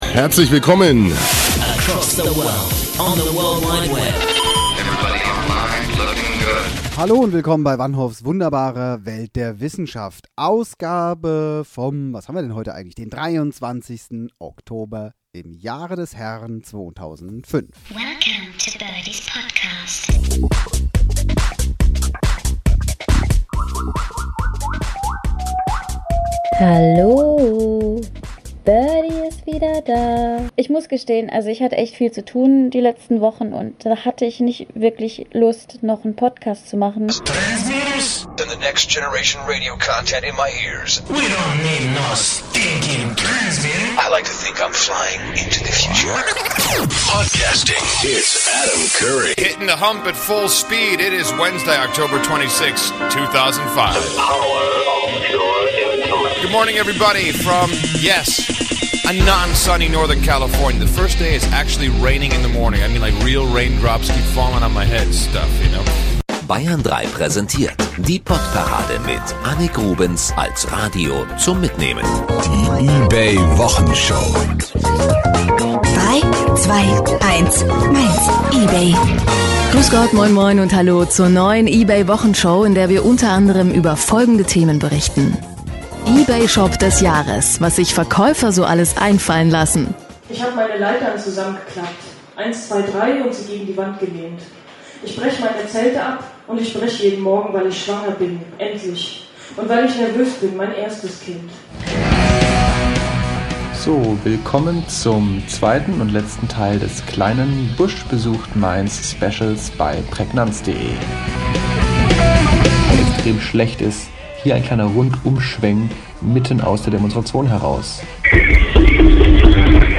podcastcollage.mp3